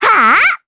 Estelle-Vox_Attack4_jp.wav